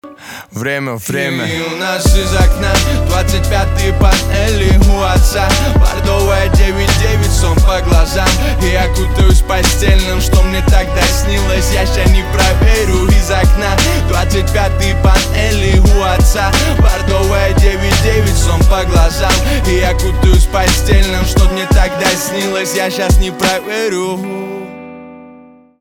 русский рэп
битовые , басы , гитара
грустные